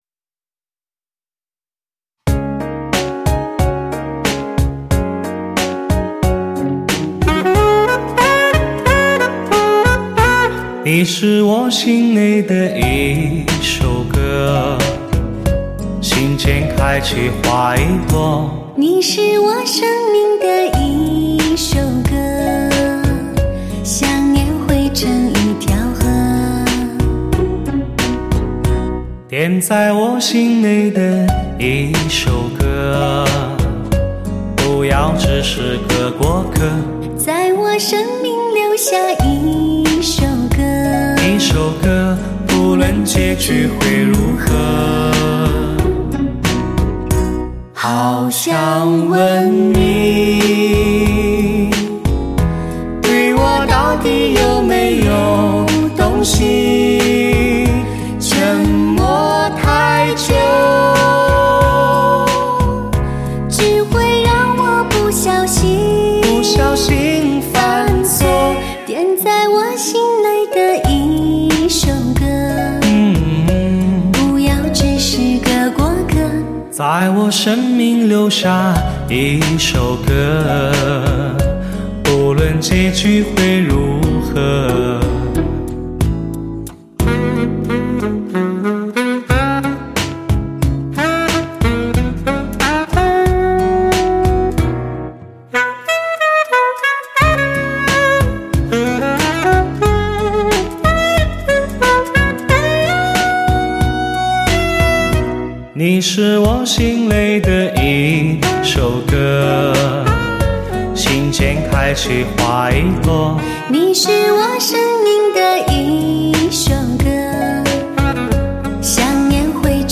母带级音质 发烧新体验
全面恢复黑胶唱片的空气感和密度感